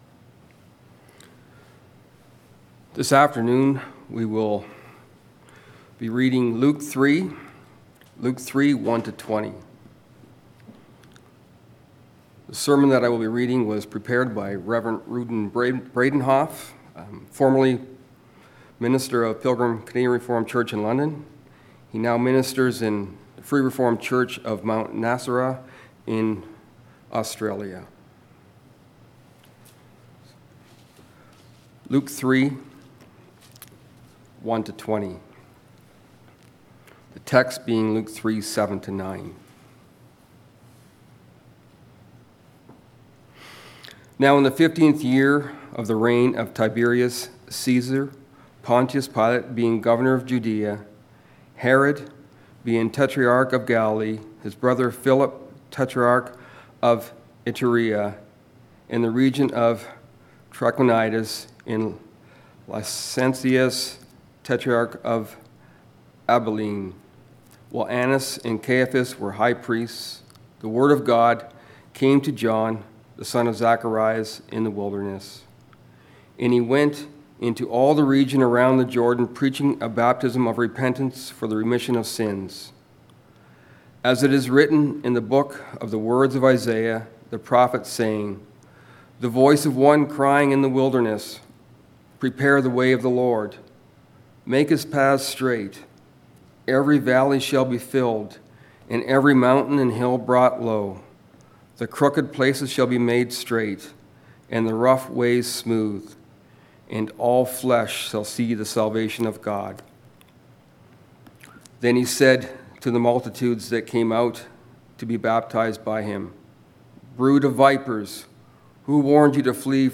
Passage: Luke 3: 1-20 Service Type: Sunday Afternoon Reading Sermon